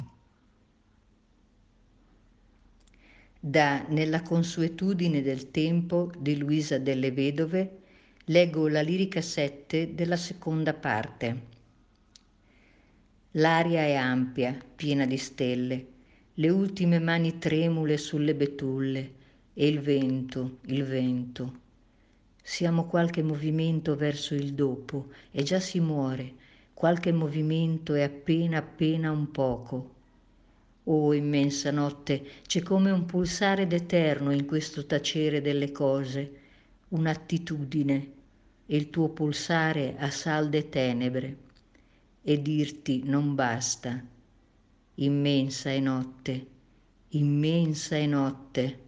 Lettura